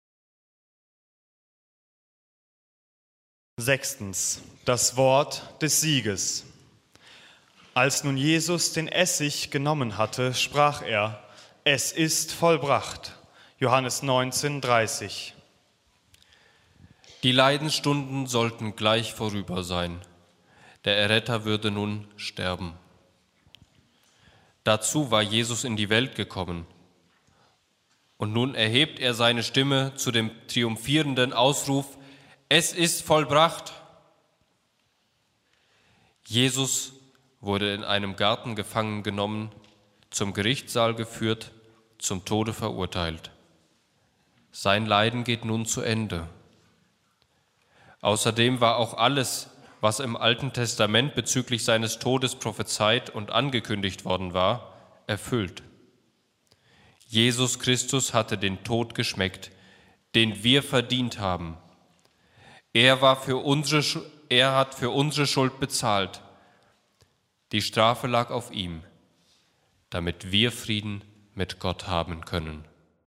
Passionssingen 2026
Lesung